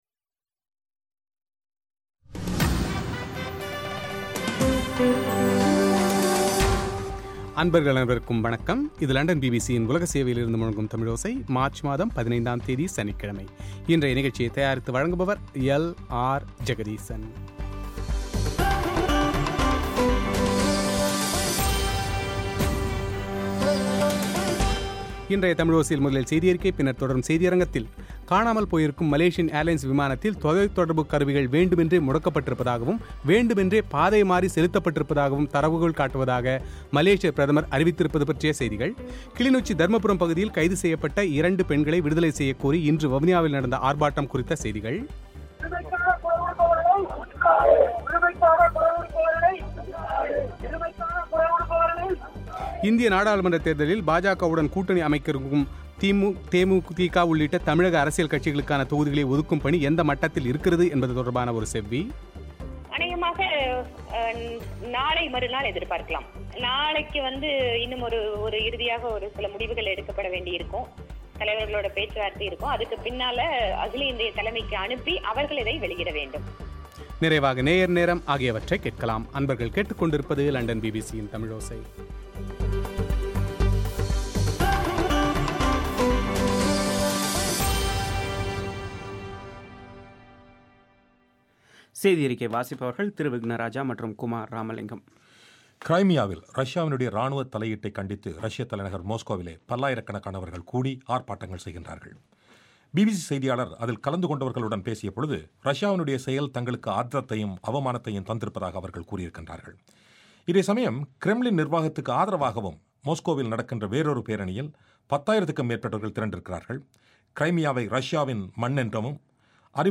பிரத்யேக பேட்டி